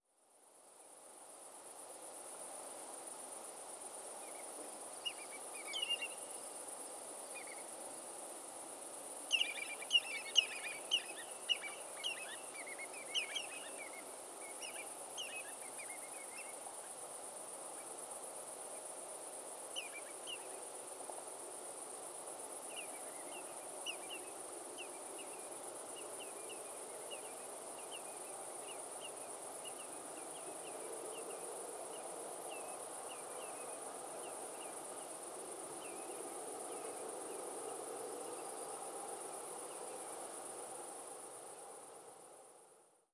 Bilurico común
Canto
Durante a migración e o inverno, pode formar grupos con outras aves limícolas, e a súa característica chamada aguda e repetitiva, un “tuit-tuit”, faio facilmente recoñecible mesmo cando non é visible.